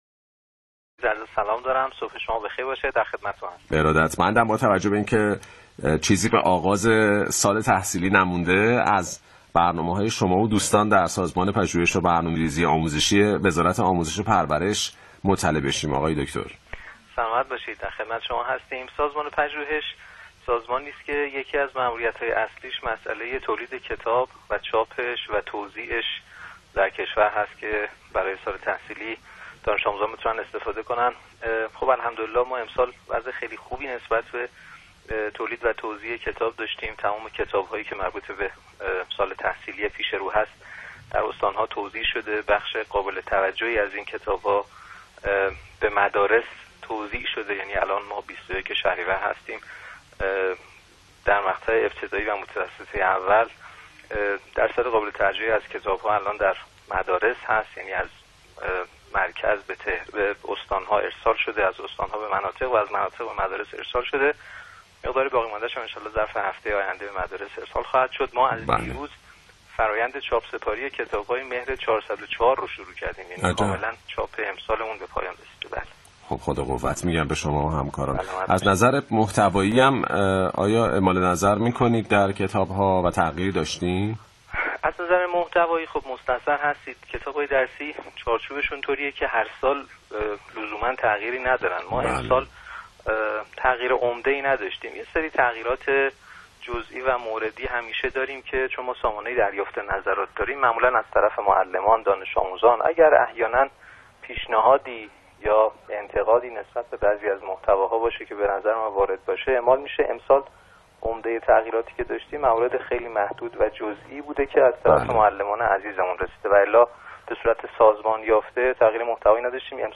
به گزارش اداره روابط عمومی و امور بین الملل سازمان پژوهش و برنامه ریزی آموزشی، صبح روز چهارشنبه 21 شهریور ماه، دکتر علی لطیفی معاون وزیر و رئیس سازمان پژوهش و برنامه ریزی آموزشی به صورت برخط پیرامون پروژه مهر و توزیع کتاب های درسی با رادیو تهران گفت و گو کرد.